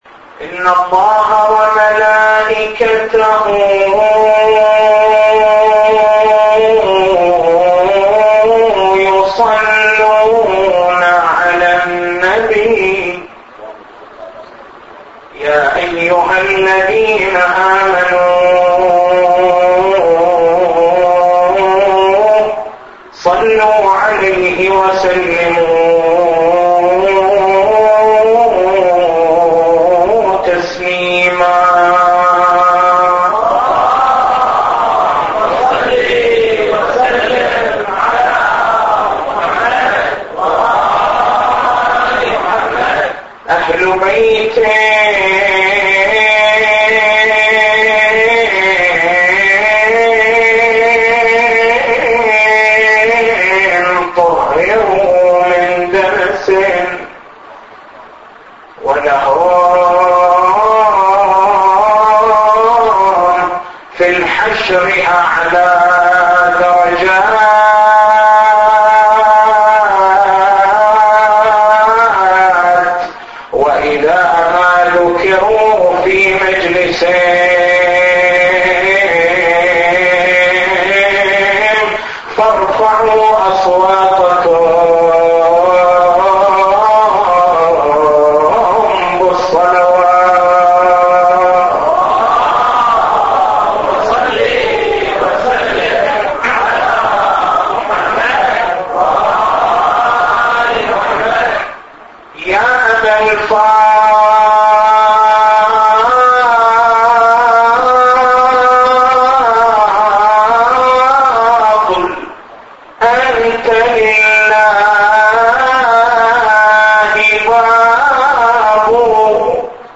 تاريخ المحاضرة